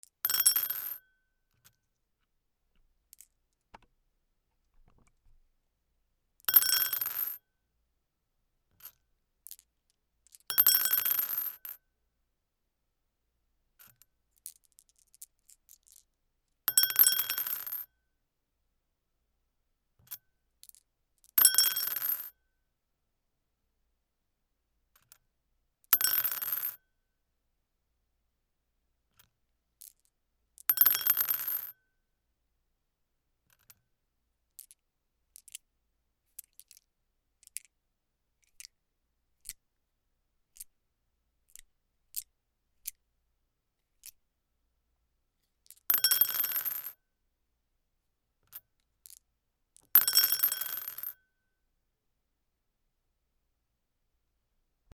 サイコロ
茶碗 D50